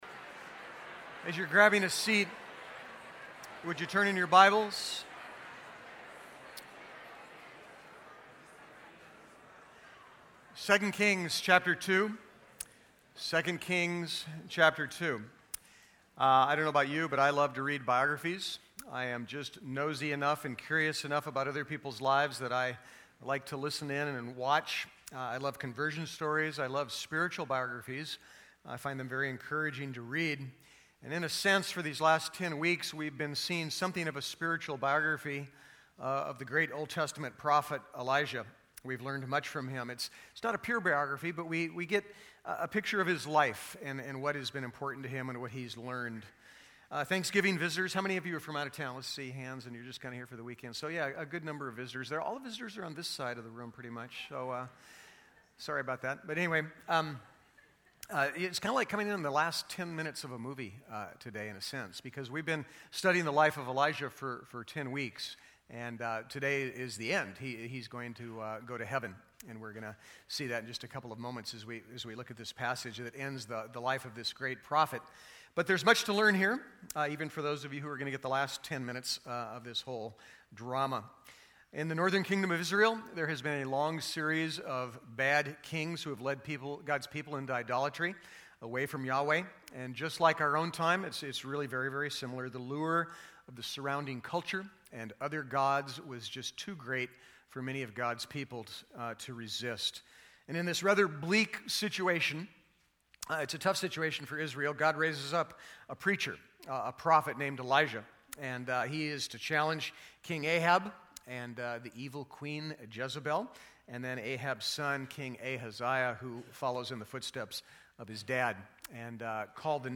2 Kings 2:1-25 Service Type: Sunday 2 Kings 2:1-25 « Falling Through the Lattice Advent 2012